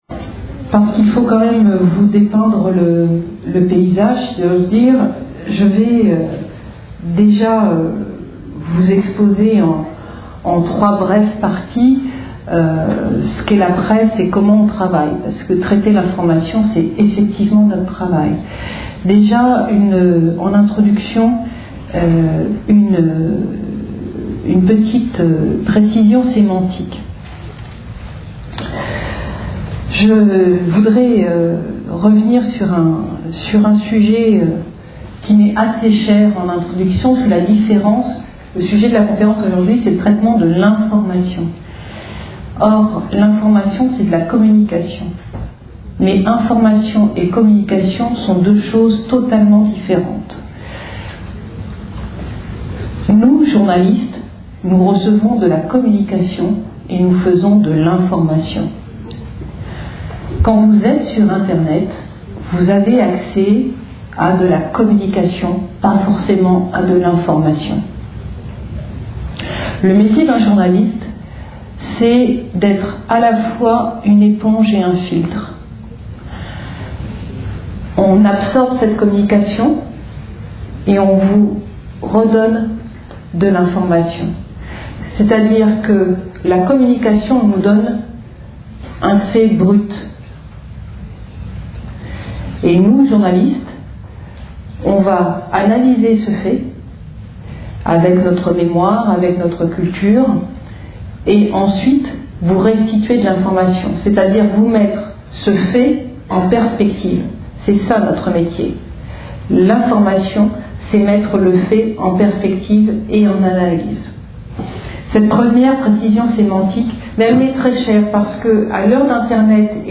Une conférence de l'UTLS au Lycée Le traitement de l'information